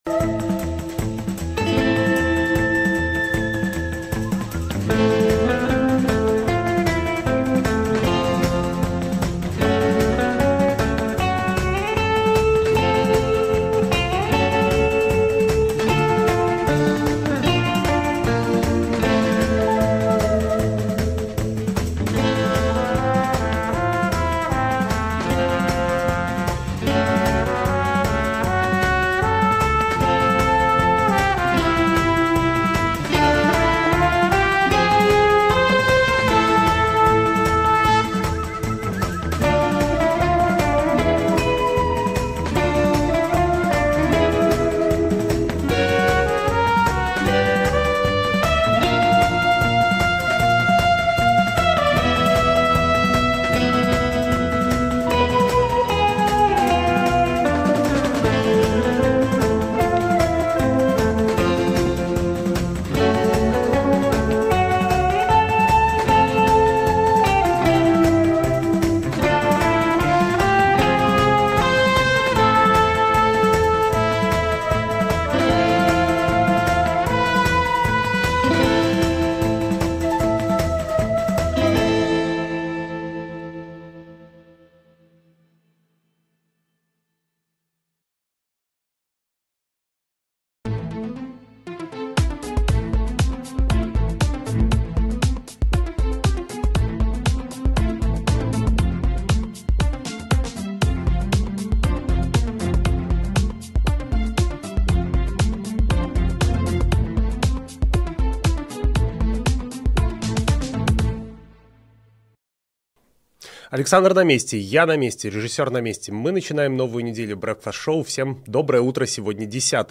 Обсудим с экспертами в прямом эфире все главные новости.